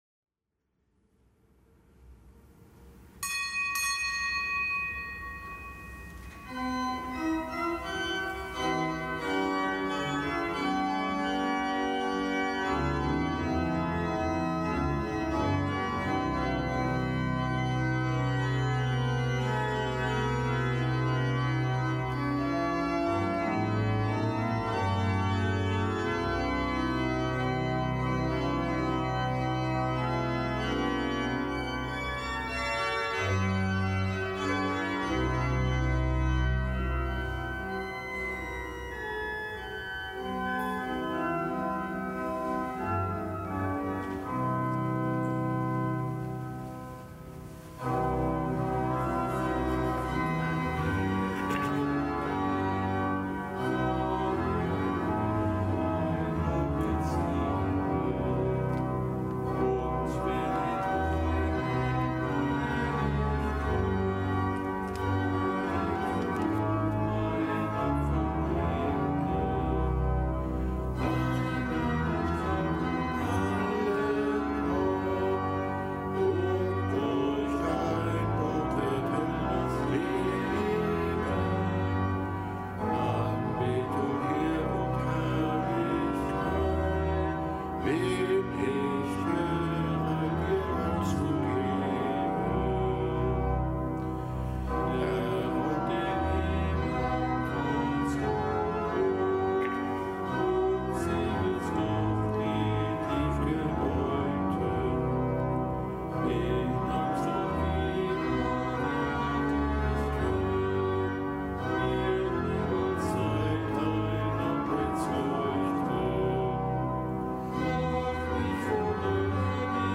Kapitelsmesse aus dem Kölner Dom am Gedenktag der heiligen Scholastika, Jungfrau. Zelebrant: Weihbischof Dominikus Schwaderlapp